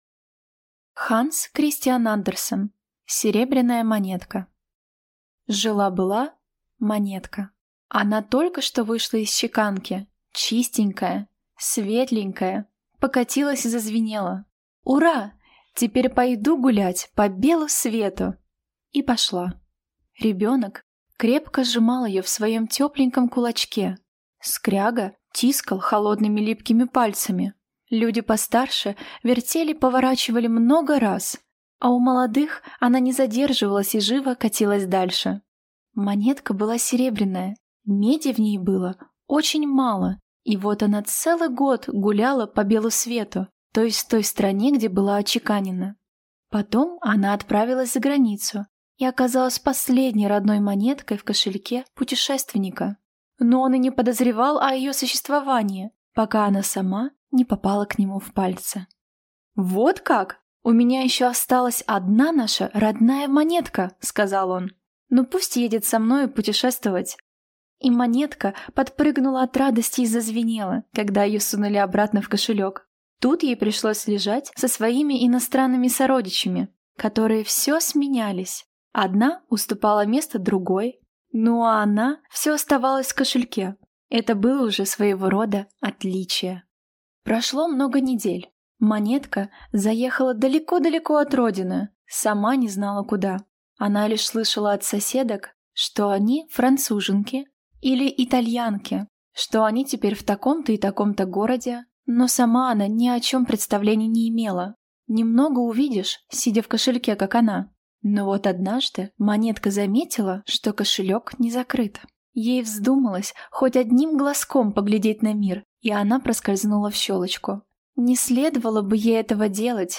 Аудиокнига Серебряная монетка | Библиотека аудиокниг